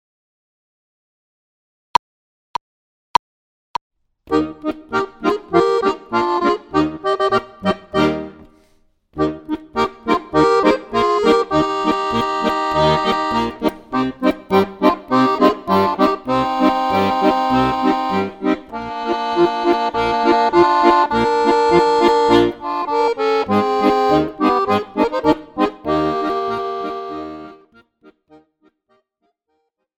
Besetzung: Tuba